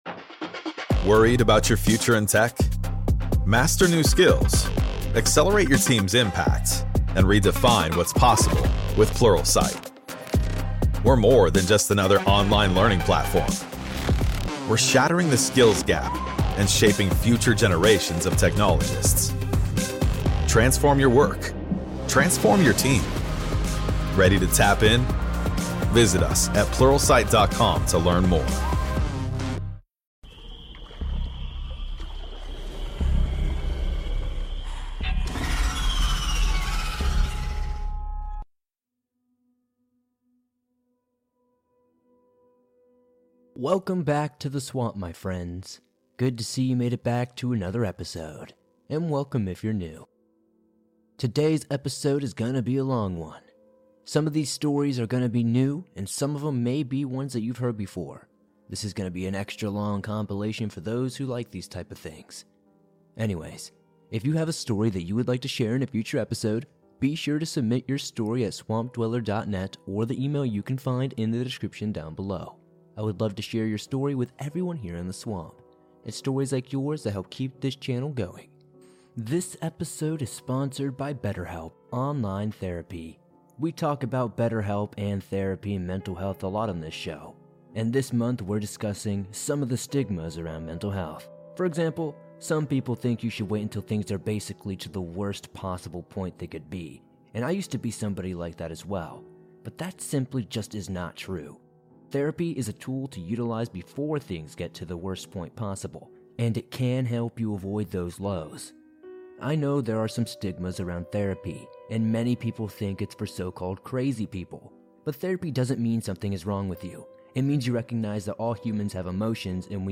There are some truly disturbing things going on in the world. Enjoy over an hour of the creepiest stories I have read in the past few weeks.